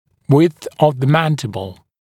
[wɪdθ əv ðə ‘mændɪbl][уидс ов зэ ‘мэндибл]ширина нижней челюсти